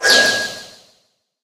punk_buzz_atk_vo_08_edit_02.ogg